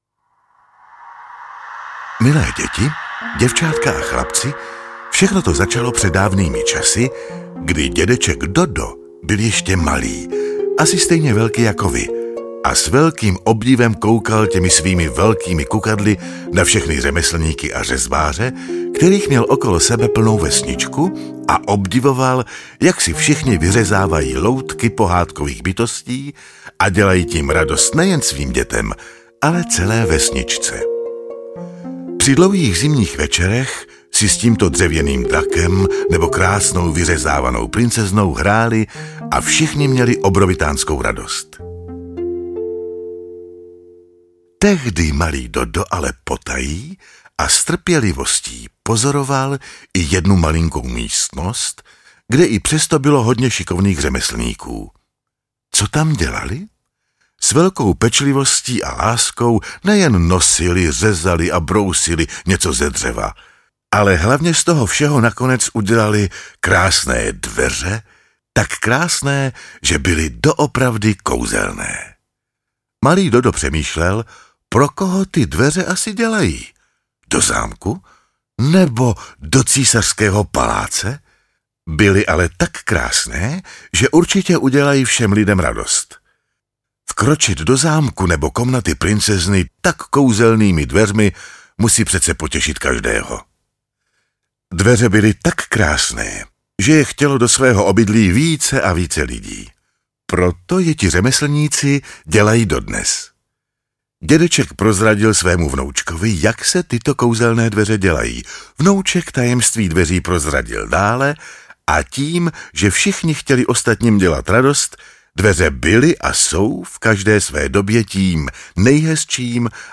Kouzelné dveře - audiokniha obsahuje kouzelný příběh, jehož autorem je Zbyněk Dokulil a Michaela Ragulová.